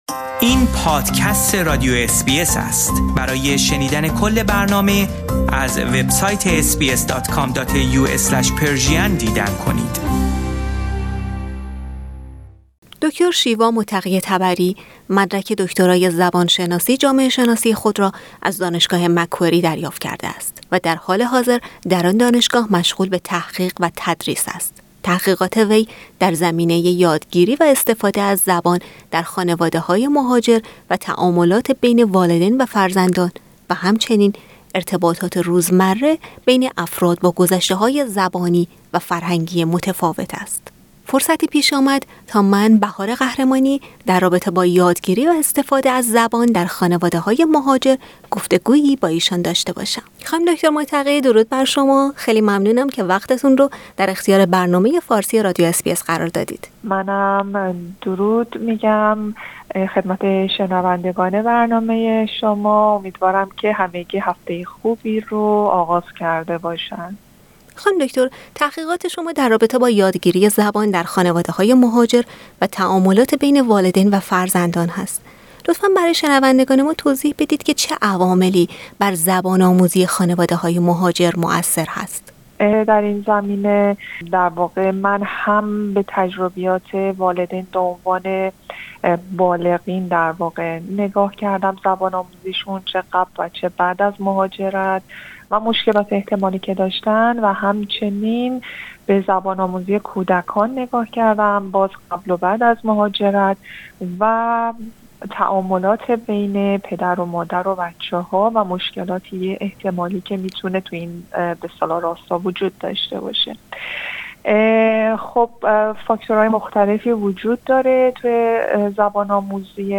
اس بی اس فارسی